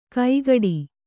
♪ kaigaḍi